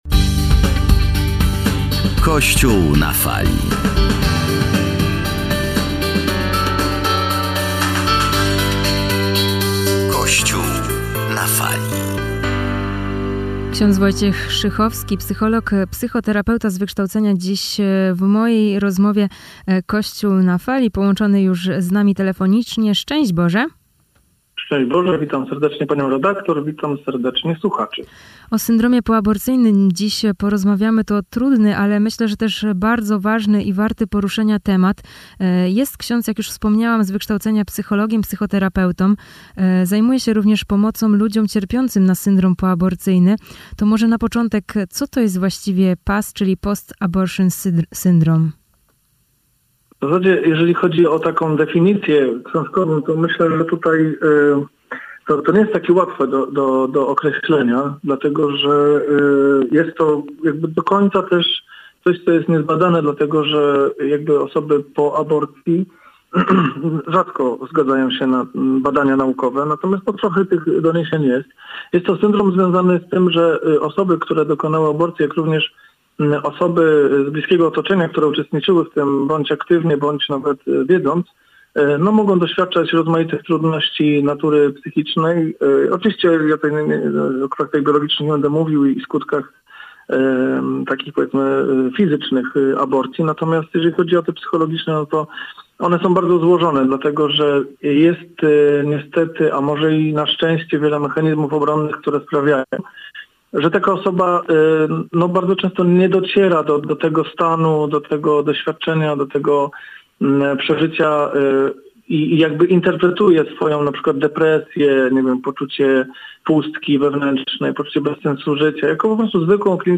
Poniżej cała rozmowa